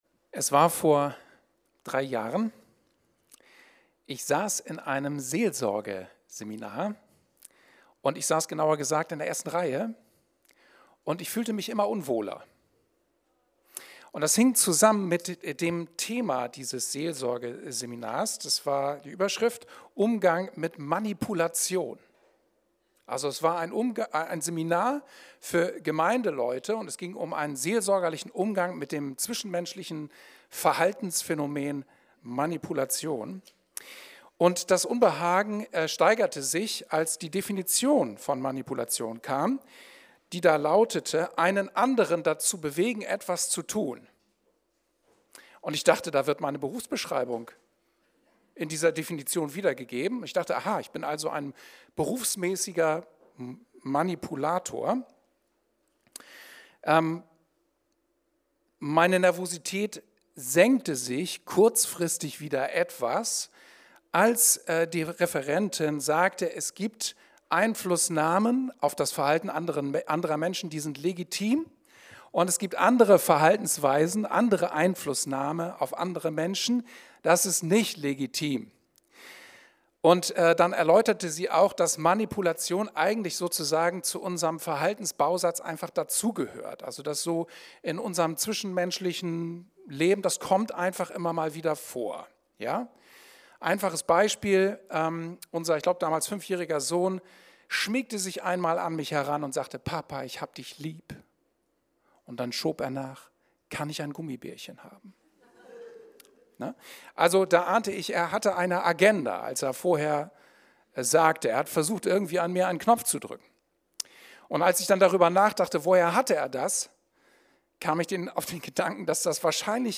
Umleitung ~ Anskar Wetzlar // Predigt Podcast